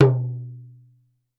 07 TALKING D.wav